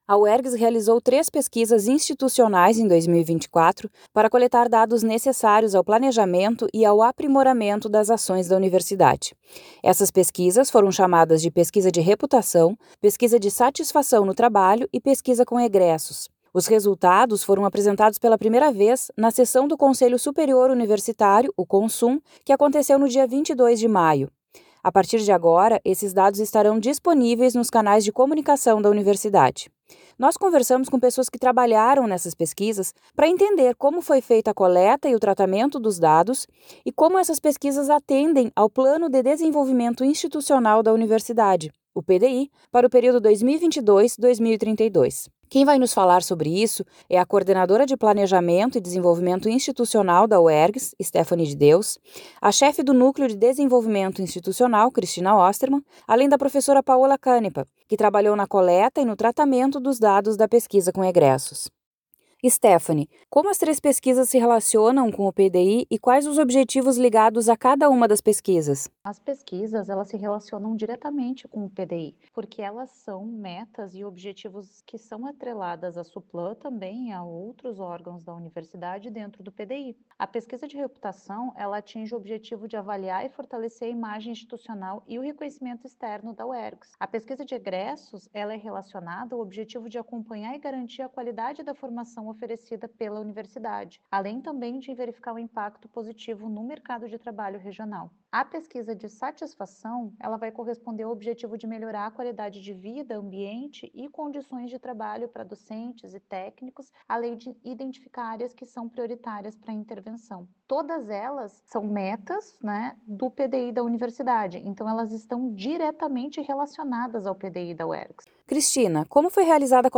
Entrevista sobre o levantamento e o tratamento dos dados das pesquisas